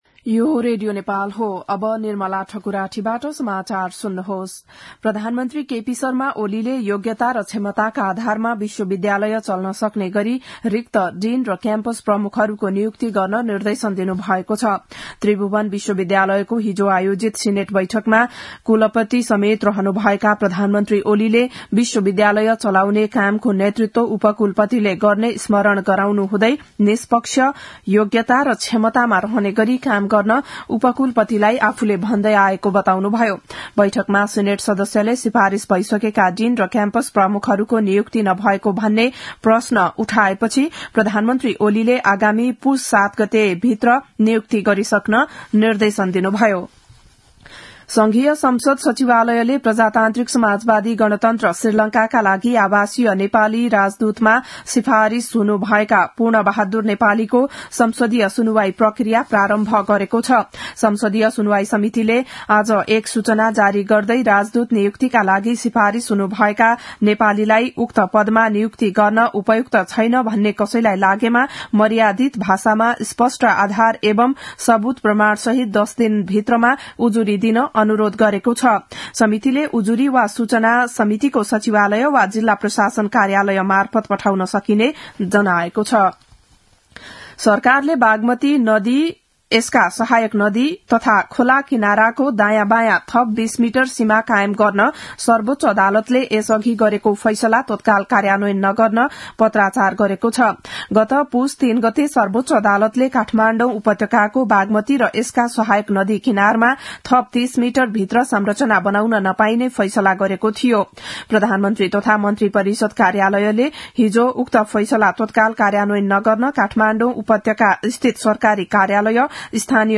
बिहान ११ बजेको नेपाली समाचार : २९ मंसिर , २०८१
11-am-nepali-news-1-12.mp3